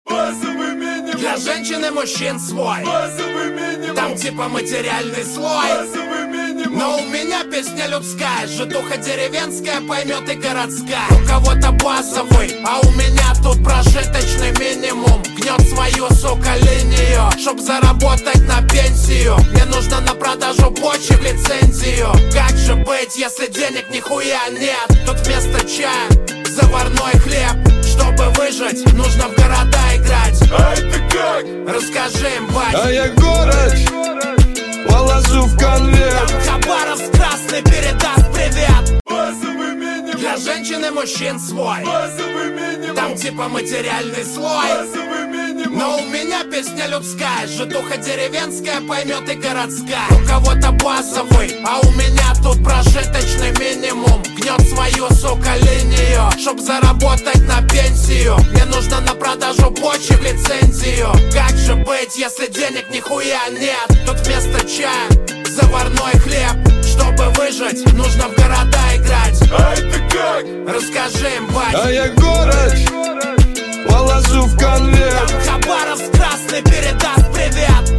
Качество: 128 kbps, stereo
Рок музыка 2025